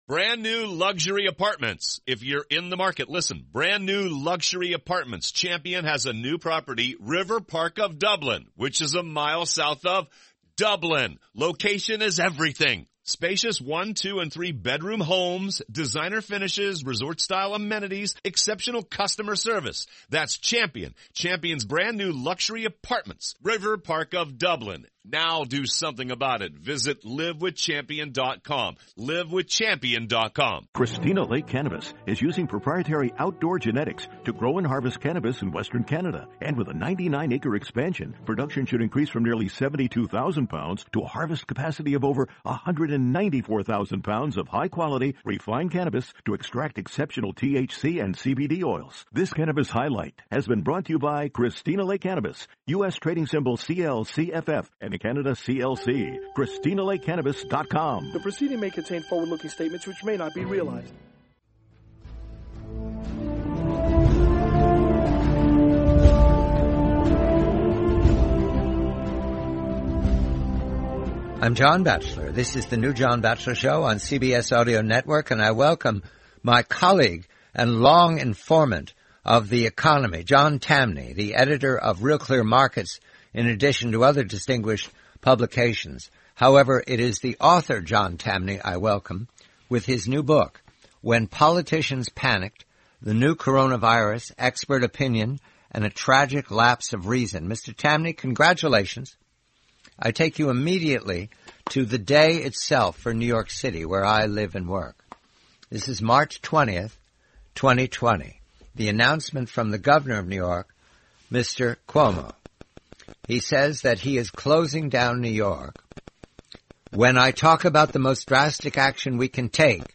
The complete,forty-minute interview.